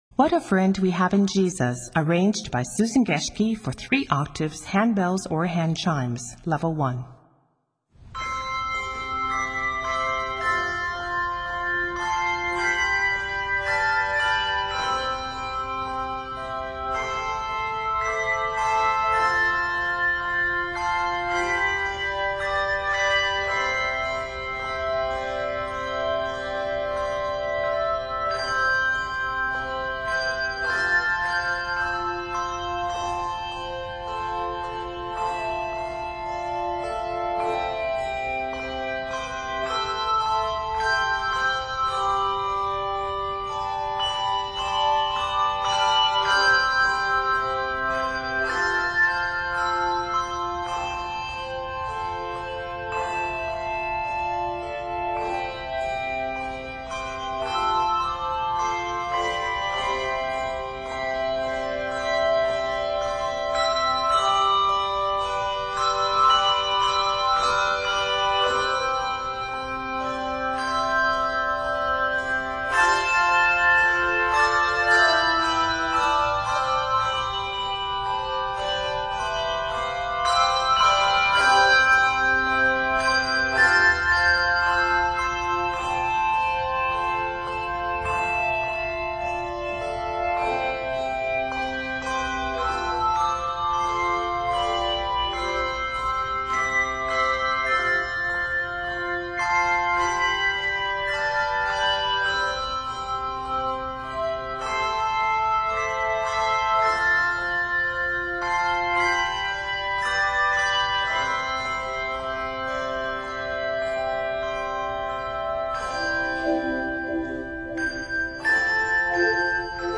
echo and LV techniques
gentle arrangement